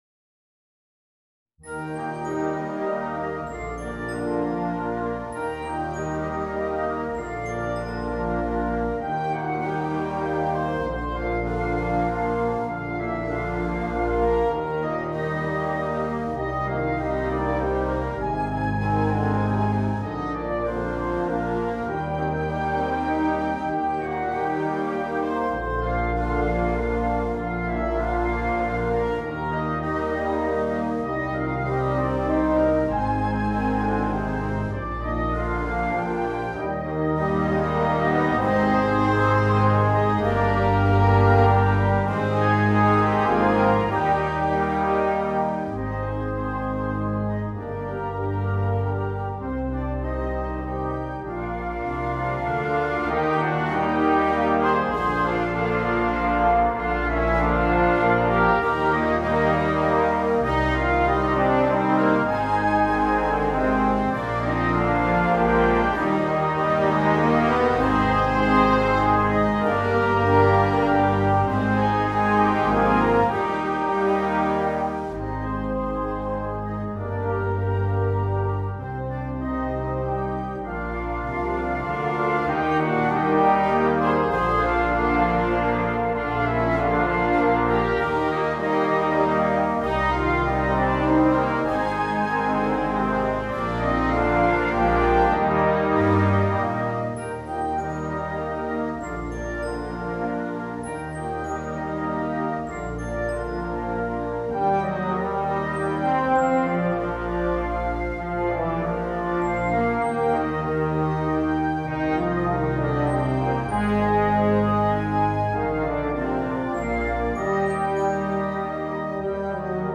for Wind Band
Instrumentation: Wind Band